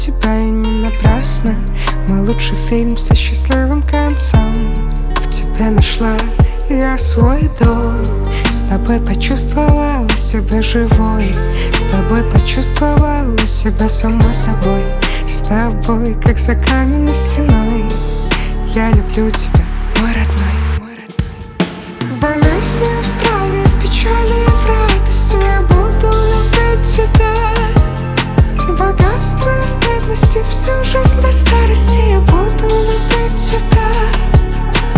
Жанр: Поп музыка / Альтернатива / Русский поп / Русские
Indie Pop, Alternative